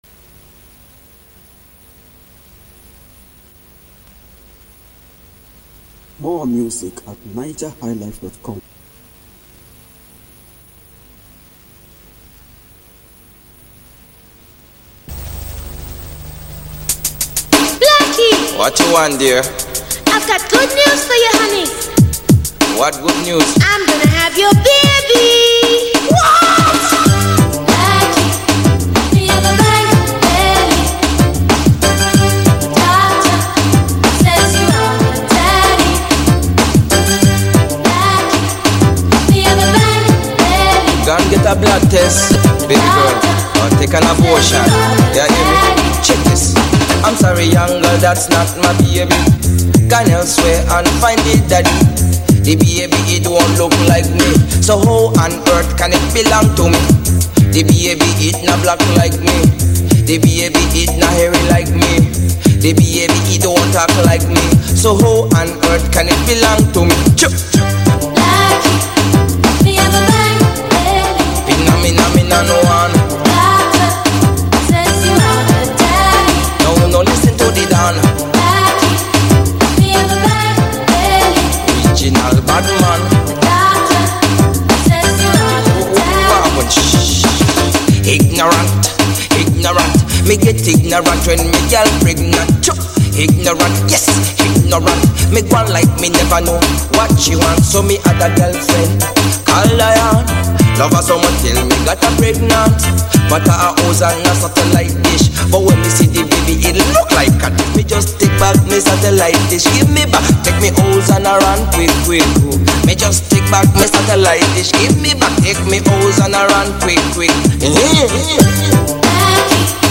Home » Ragae